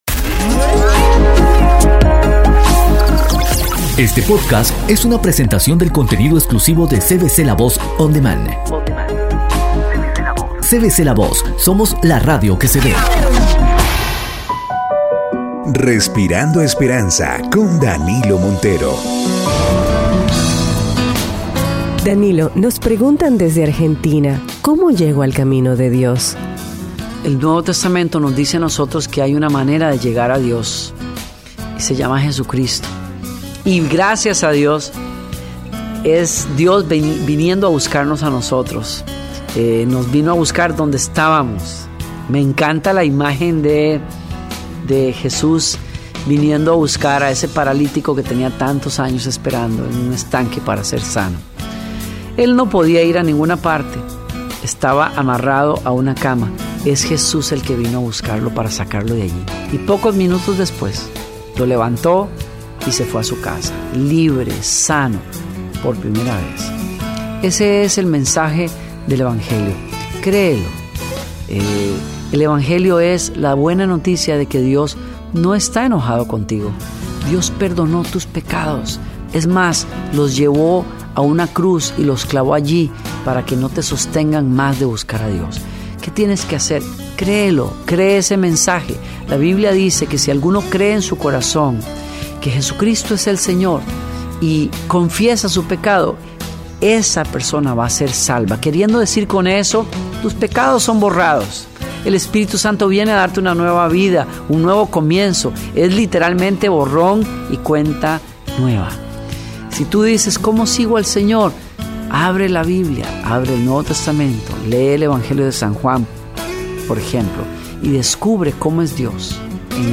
El pastor Danilo Montero explica que Jesús es el único camino para llegar a Dios y que gracias a Él podemos obtener vida eterna.